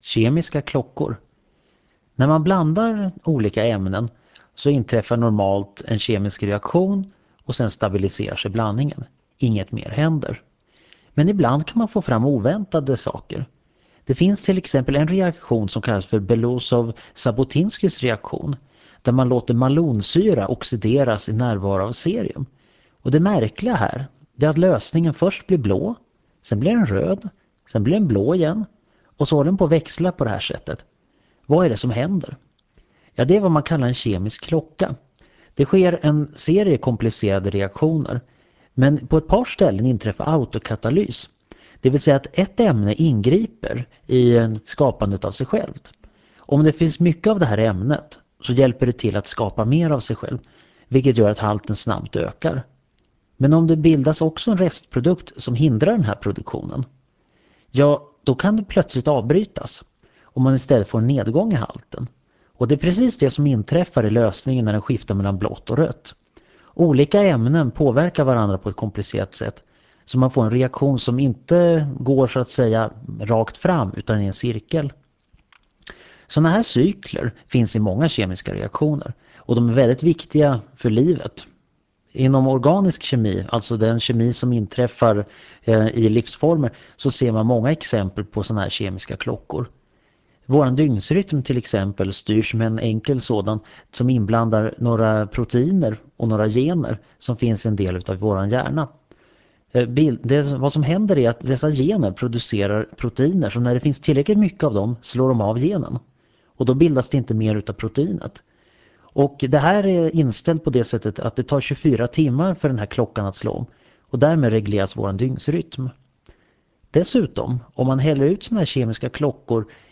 Föredraget handlar om kemi och sänds den 31 oktober 1999 i Förklarade_Fenomen.